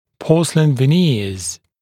[‘pɔːsəlɪn və’nɪəz][‘по:сэлин вэ’ниэз]фарфоровые виниры